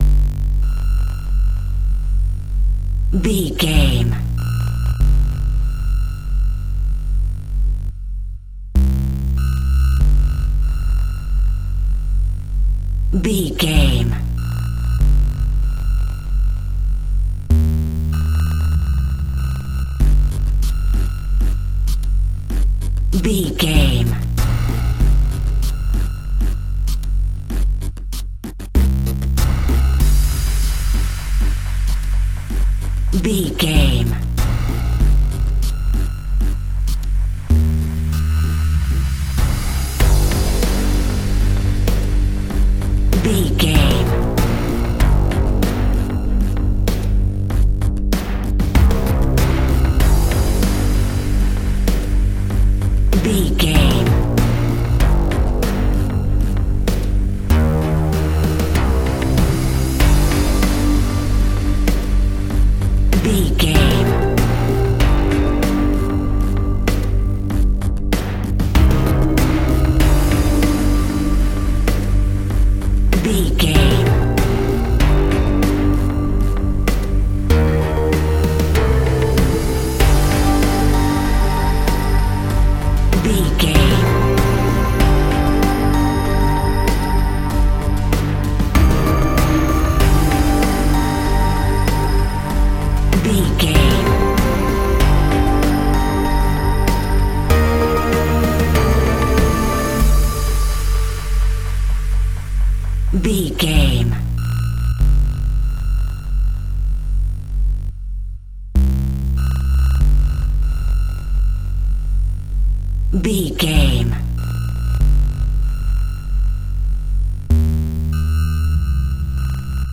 Aeolian/Minor
ominous
dark
haunting
eerie
synthesiser
tense
electronic music